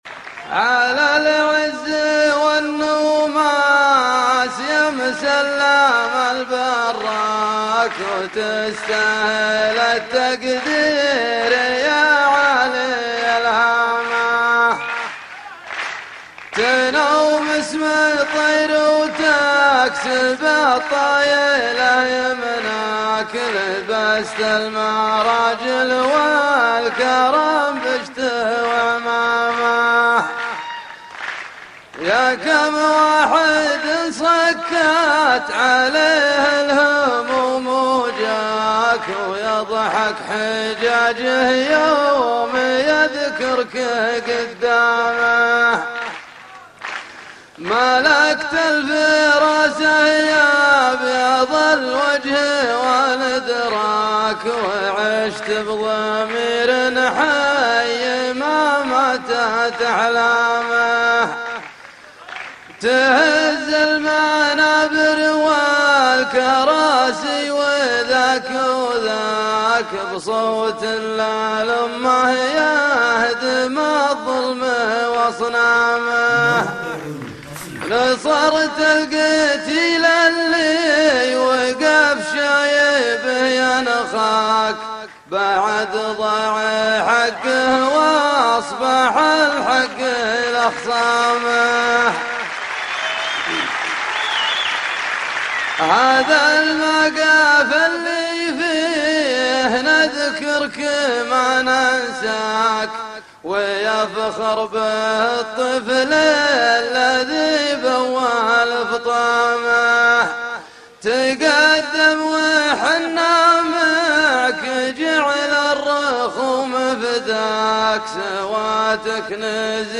يامسلم البراك - شيله - امسية ليالي فبراير 2012